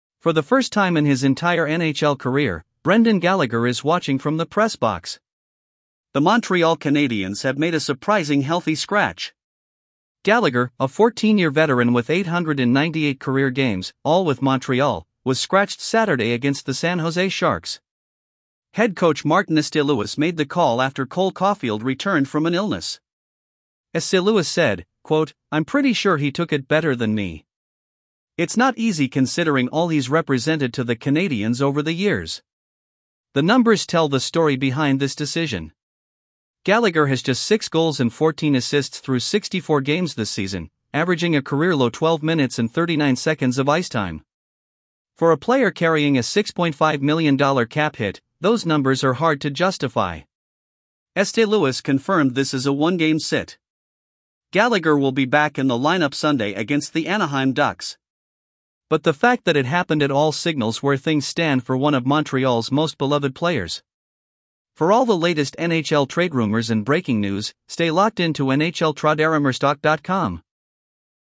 AI Voice